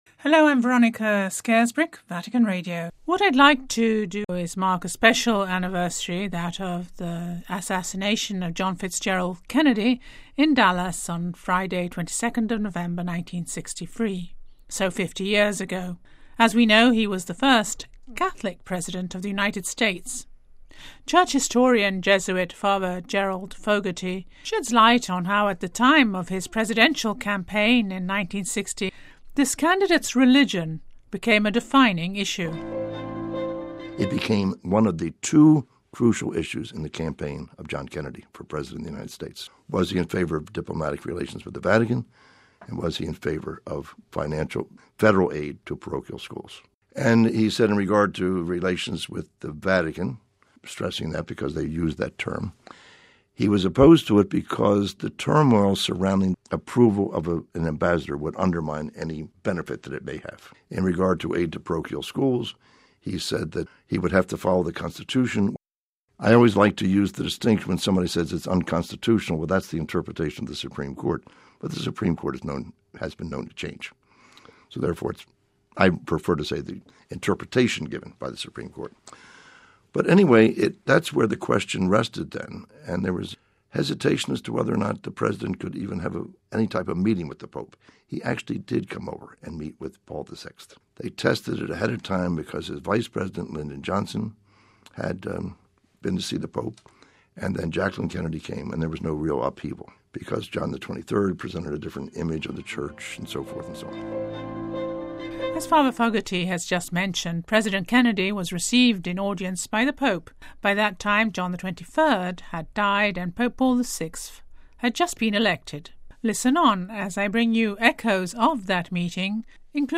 In this programme you can also hear a selection of Vatican Radio archive sound relating to the words of Paul VI to President Kennedy during an audience on July 2nd 1963.